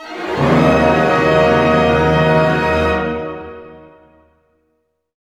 Index of /90_sSampleCDs/Roland LCDP08 Symphony Orchestra/ORC_Orch Gliss/ORC_Minor Gliss